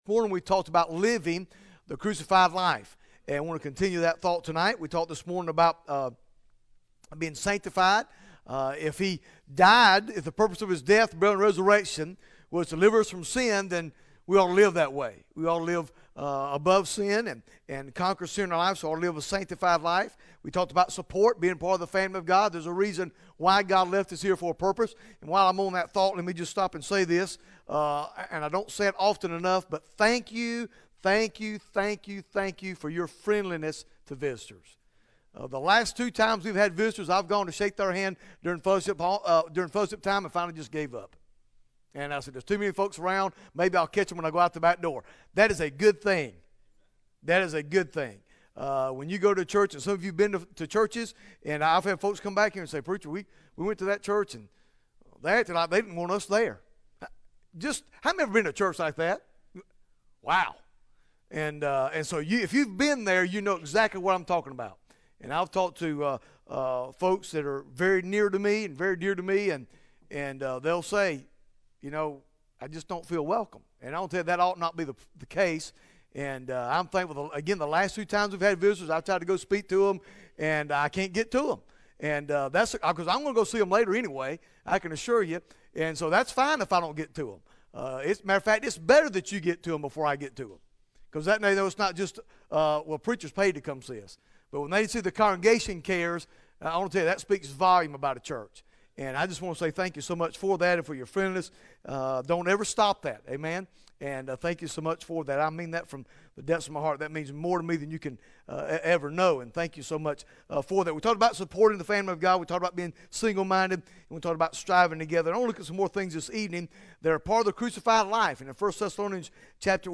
Bible Text: 1 Thessalonians 5 | Preacher